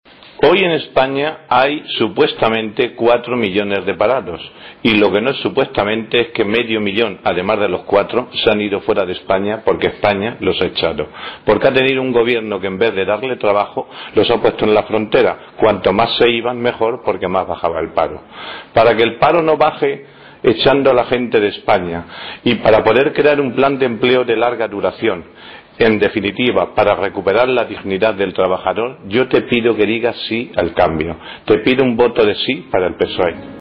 Acto público en Albaladejo
Cortes de audio de la rueda de prensa